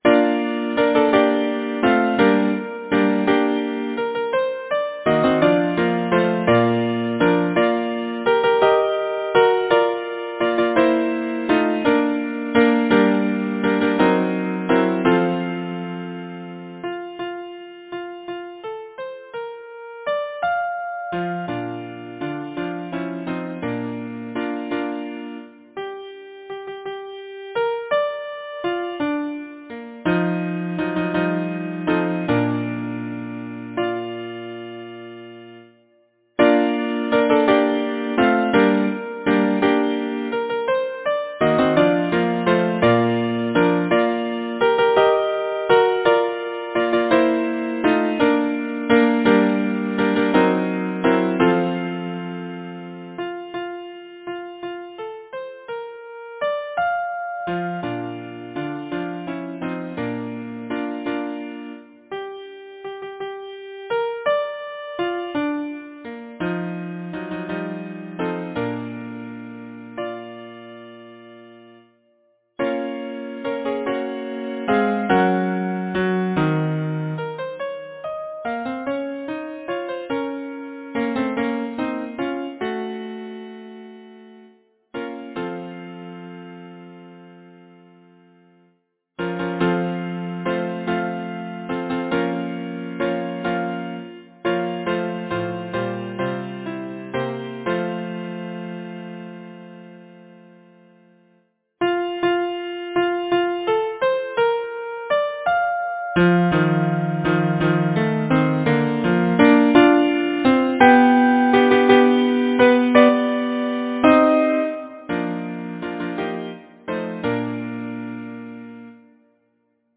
Title: A Life Lesson Composer: Jessie Louise Pease Lyricist: James Whitcomb Riley Number of voices: 4vv Voicing: SATB Genre: Secular, Partsong
Language: English Instruments: A cappella